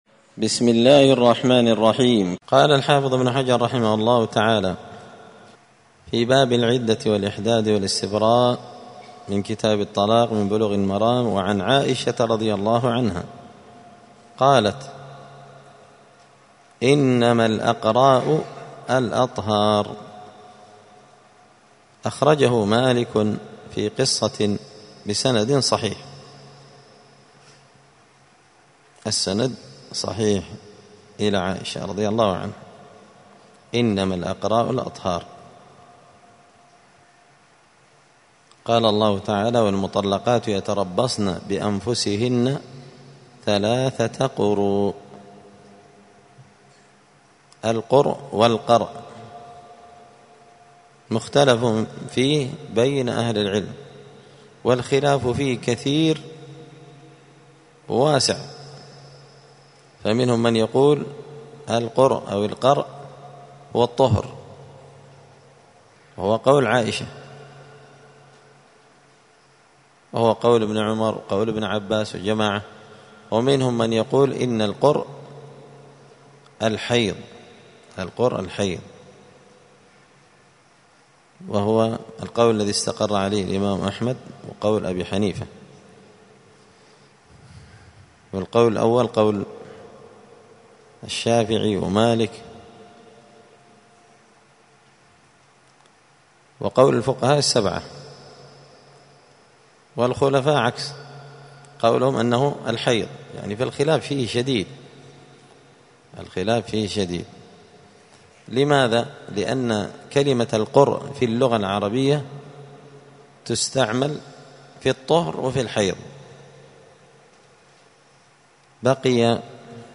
*الدرس الرابع والعشرون (24) {تابع لباب العدة الإحداد والاستبراء}*